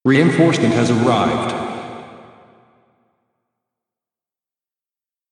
I just found a pretty nice text-to-speech that has a really great voice that sounds perfect for broadcast-like recording :smiley:
I changed the voice for one of them to see what it would sound like with a deeper voice saying it and another one sounded a little glitchy, but I liked the way it sounded :stuck_out_tongue: (It sounded like the voice was coming from the reactor itself and that the glitch was coming from the hits on the reactor.)